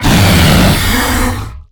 attack_3.ogg